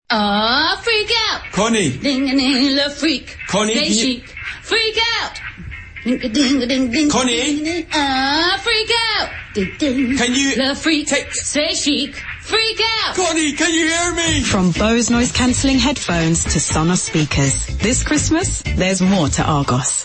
Connie and Trevor are back for another year as Argos’s Christmas mascots and their recognisable voices are well suited for the campaign’s amusing radio spots.